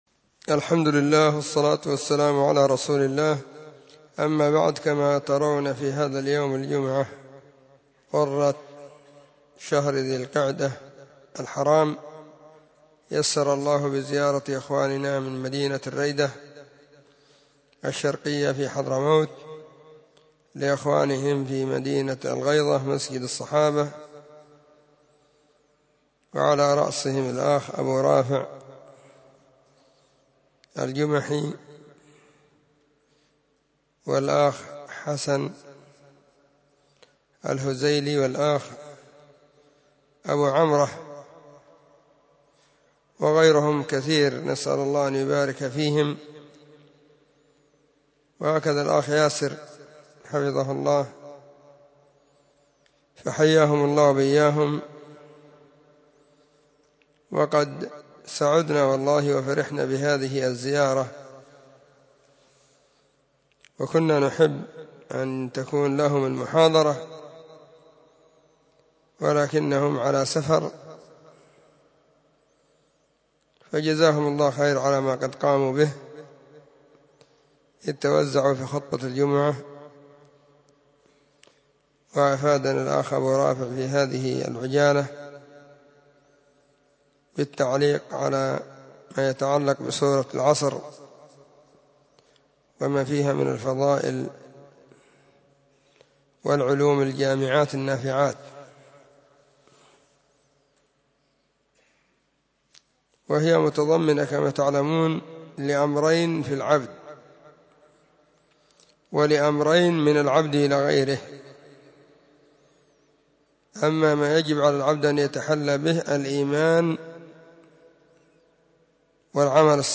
السبت 2 ذو القعدة 1442 هــــ | كلمــــات | شارك بتعليقك